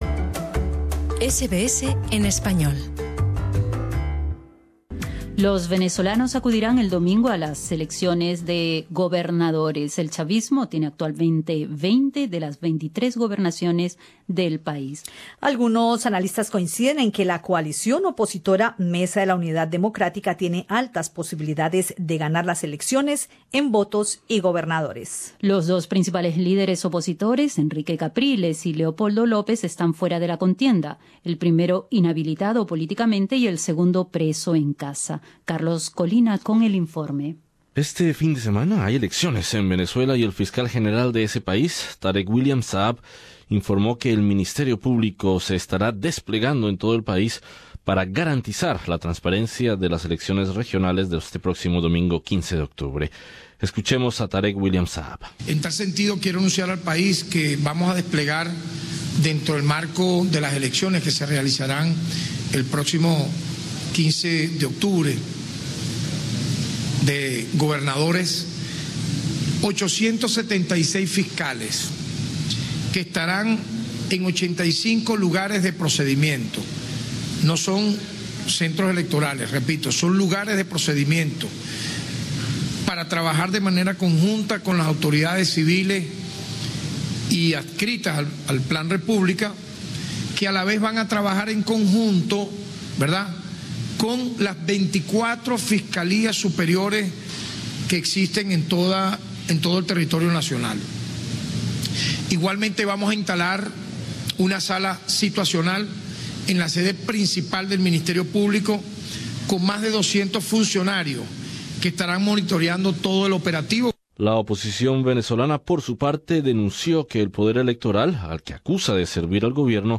Analistas coinciden en que la coalición opositora Mesa de la Unidad Democrática tiene altas posibilidades de ganar las elecciones, en votos y gobernadores. Entrevista con el politólogo experto en sistemas electorales